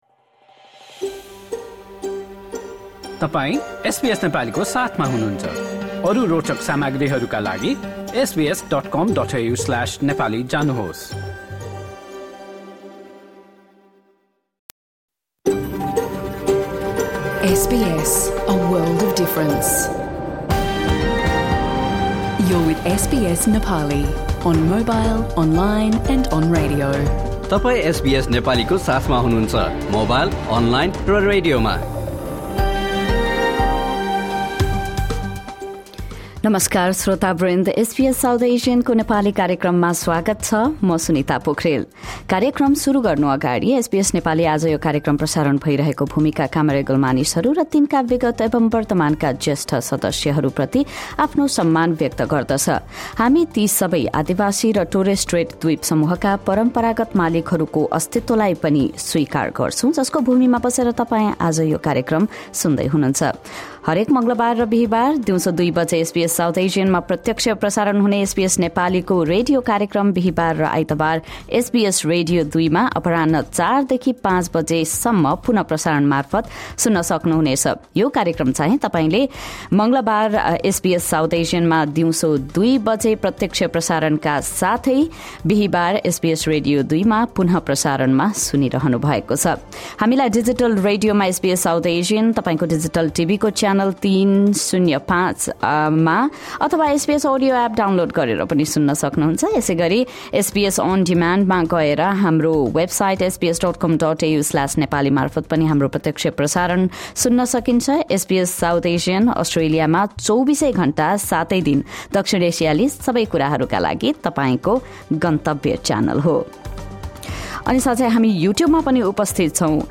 Listen to our radio program, first aired on SBS South Asian on Tuesday, 10 March 2026 at 2 PM, featuring weekly Australian news, the rise of Balendra Shah in Nepali politics, updates on Nepal’s elections, conversations around International Women’s Day, and other topics.